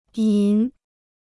银 (yín): silver; silver-colored.